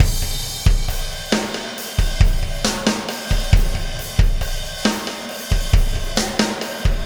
Pulsar Beat 01.wav